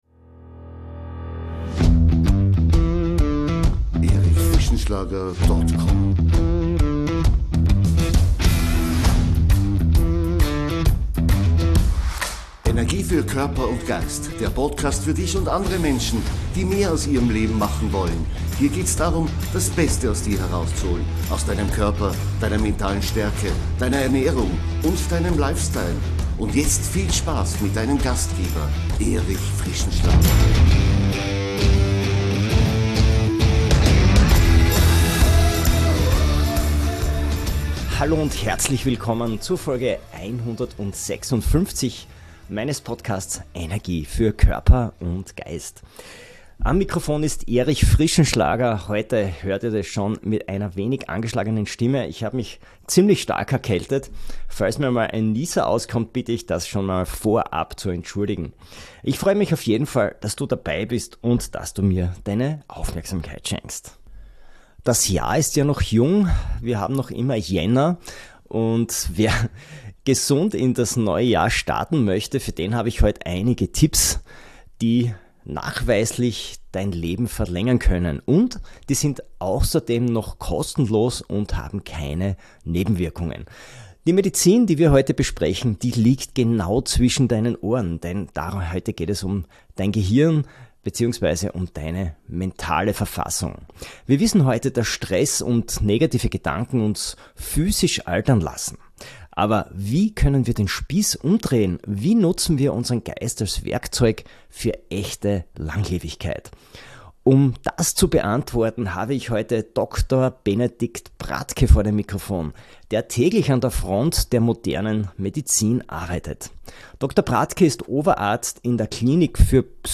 Es ist ein Gespräch, das Mut macht und zeigt: Gesundheit beginnt im Kopf.